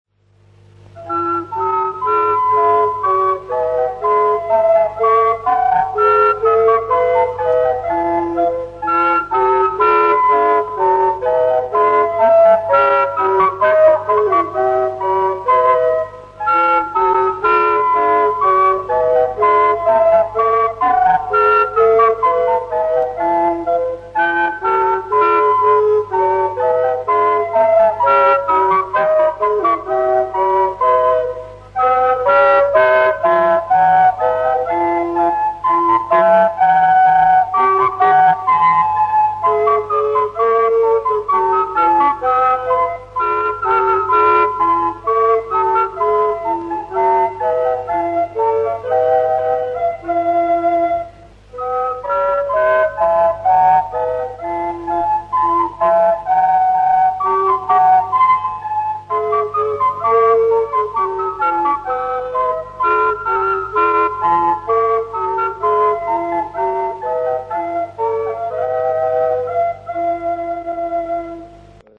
Tylko tyle udało się jak na razie odnaleźć, a ich jakość, delikatnie mówiąc, nie jest najlepsza.
Miniatura barokowa w wykonaniu fletów
Koncert we Wrześni w lipcu 1972 roku